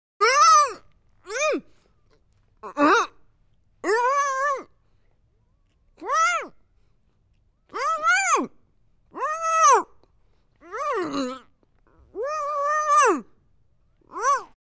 Женщина с кляпом в рту, кричащая от ужаса